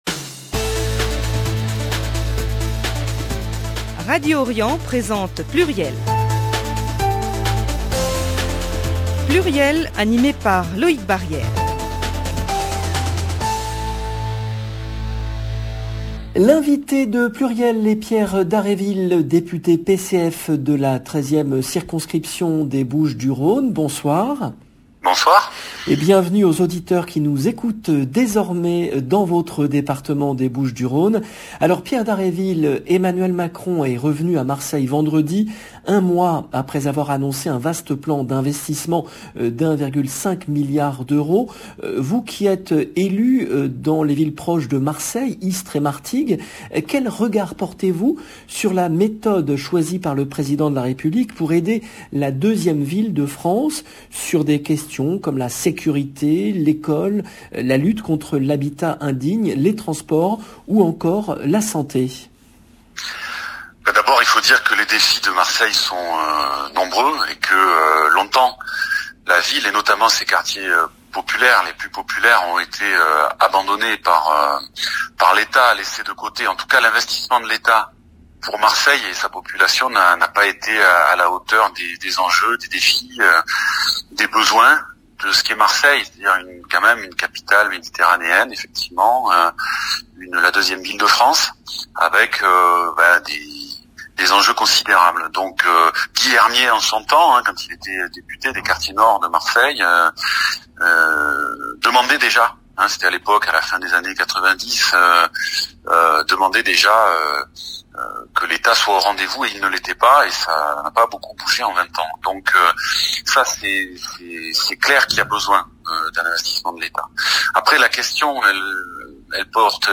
Pierre Dhareville, député PCF, invité de PLURIEL
Emission diffusée le mardi 19 octobre 2021
L’invité de PLURIEL est Pierre Dhareville, député PCF de la 13 ème circonscription des Bouches du Rhône.